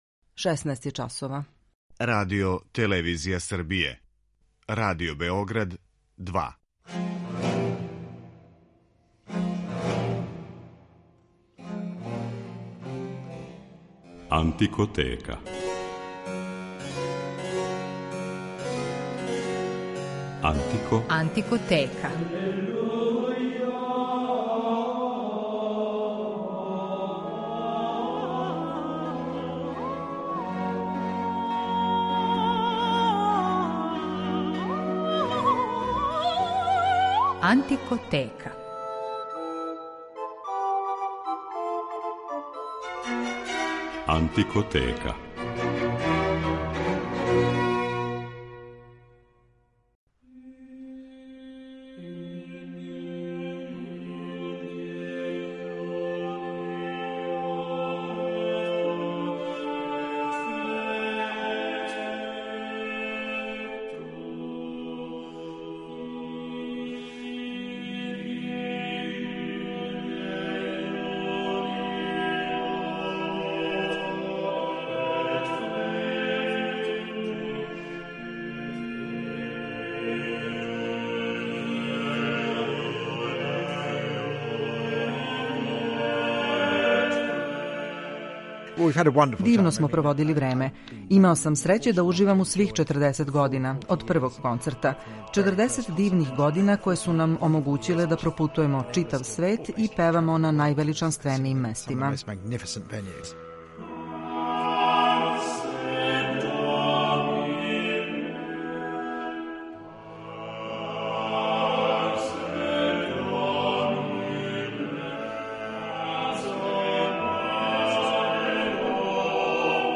Ове врхунске музичаре, који су током читаве каријере равноправно изводили и рану и савремену музику, ипак ћемо представити у литератури са којом су се прославили ‒ у музици средњег века и ренесансе. У рубрици „Антикоскоп" говоримо о преломној концертној сезони 1973/1974, која је била од историјског значаја за рану музику.